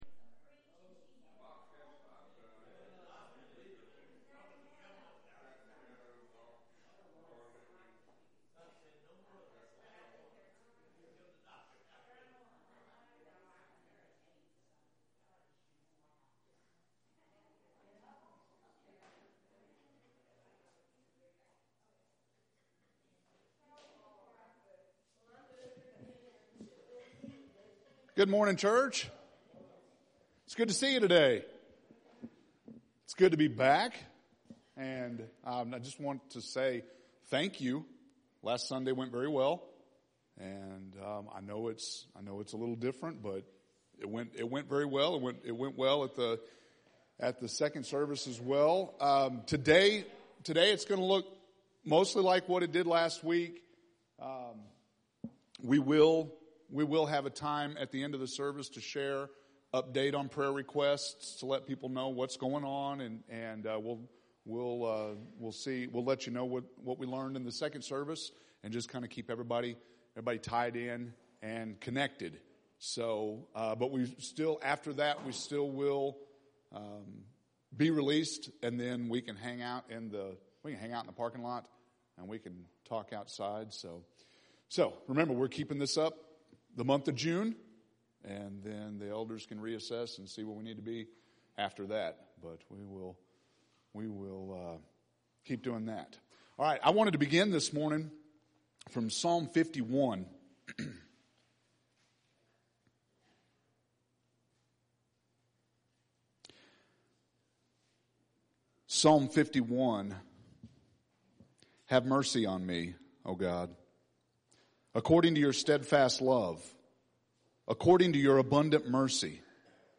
June 14th – Sermons